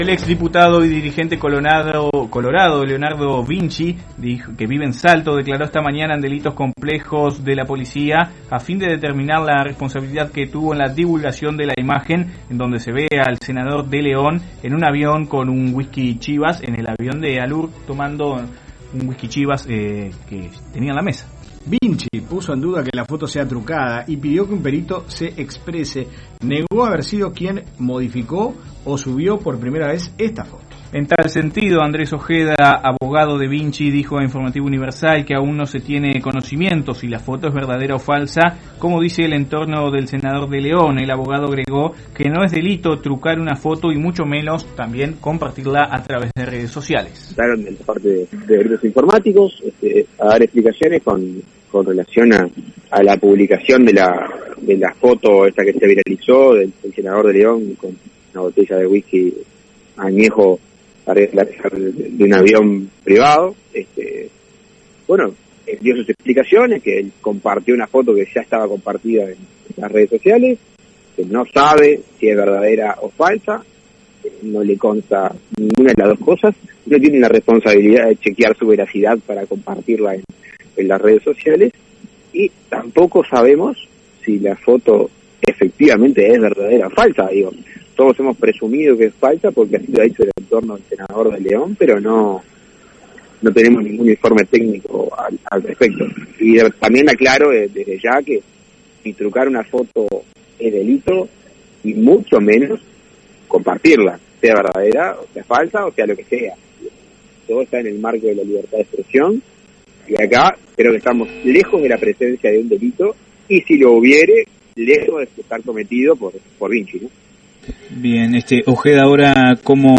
Ahora todo queda en manos de la justicia, dijo el abogado: